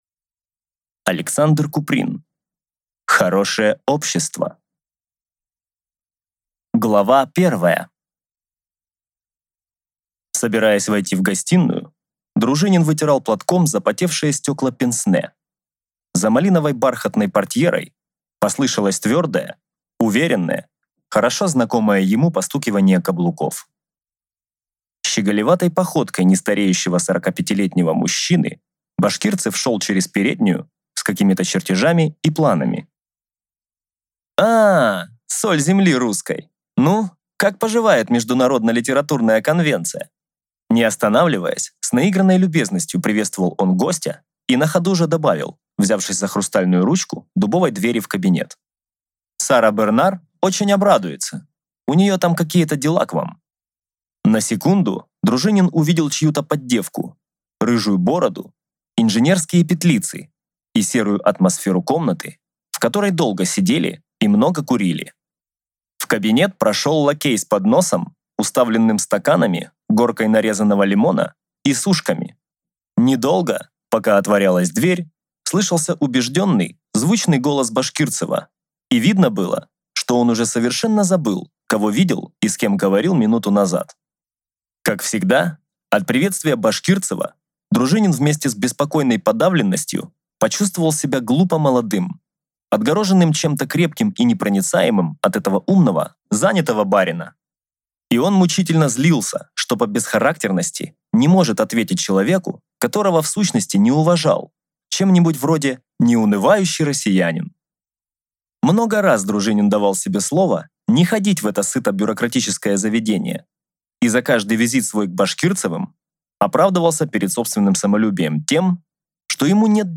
Аудиокнига Хорошее общество | Библиотека аудиокниг